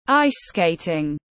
Môn trượt băng tiếng anh gọi là ice-skating, phiên âm tiếng anh đọc là /ˈaɪs skeɪt/
Ice-skating.mp3